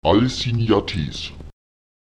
Lautsprecher al’simnates [ÇalsiÈøates] die Galaxien (falsch: al’simnatetes)